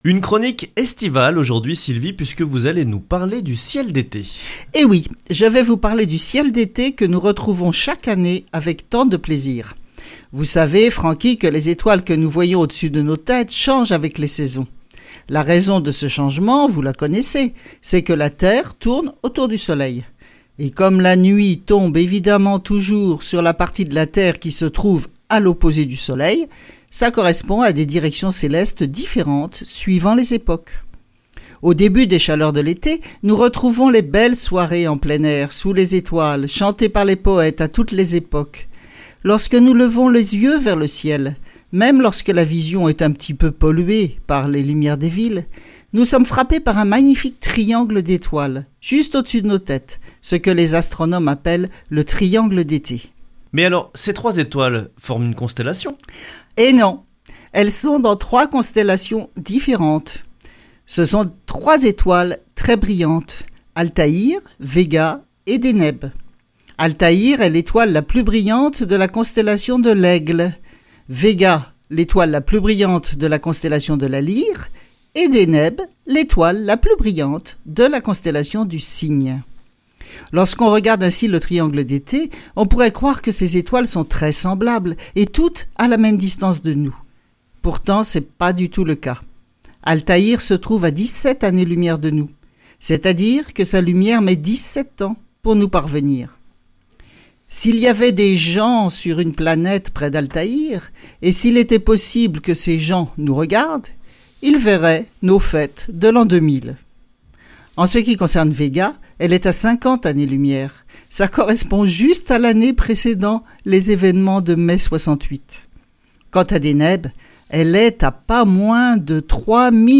Speech
Une émission présentée par
Astrophysicienne